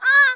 bin / pack / Sound / sound / monster / bkthird / dead_2.wav
dead_2.wav